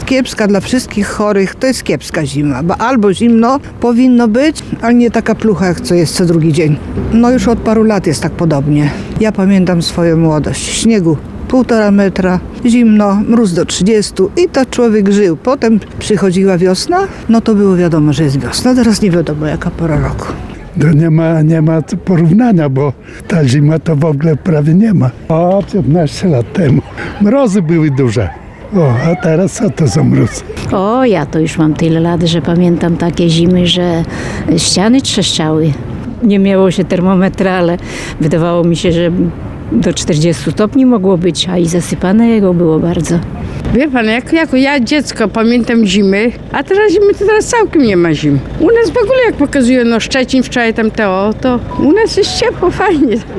– Kiepska ta zima – skarżą się przechodnie, których spotkaliśmy dziś na ulicach Suwałk. Jak mówili, brakuje im mrozu i śniegu.
sonda-zima-mp3.mp3